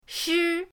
shi1.mp3